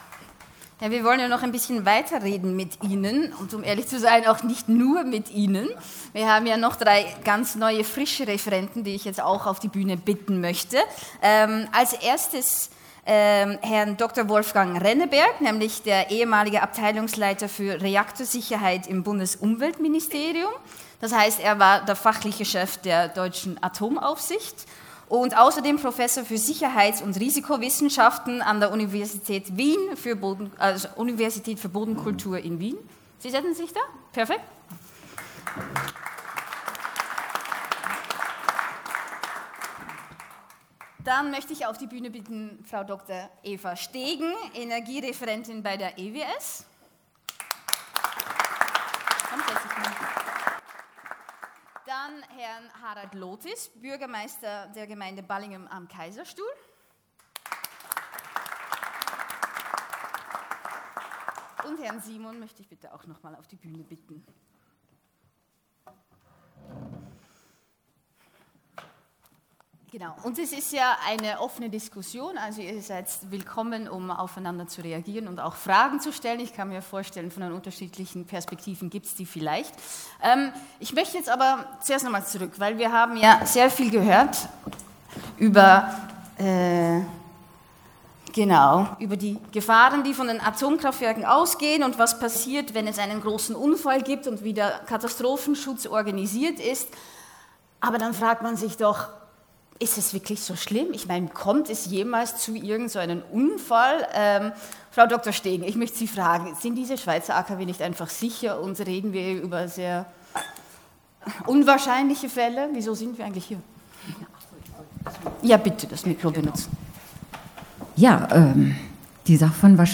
Nachrichten
03.04.2014 ++++++++O-Ton So die Stimmen von heute im Parlament.